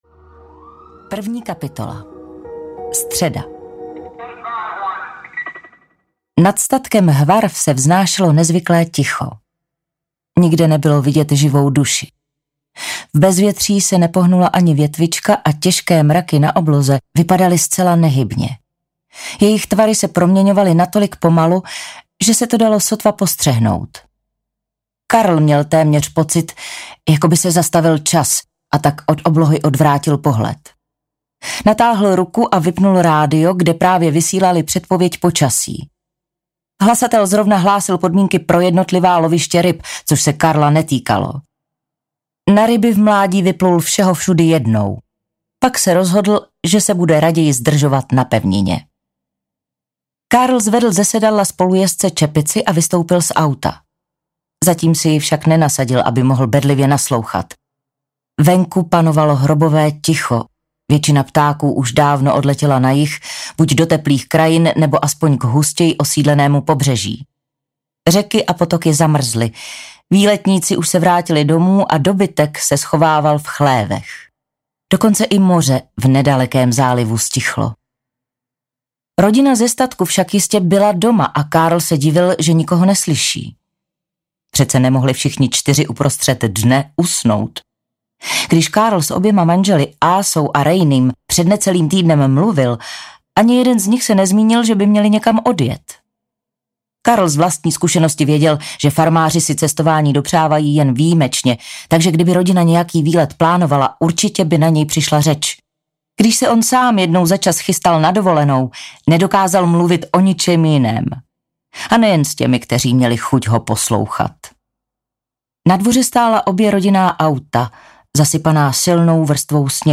Není úniku audiokniha
Ukázka z knihy
• InterpretKlára Cibulková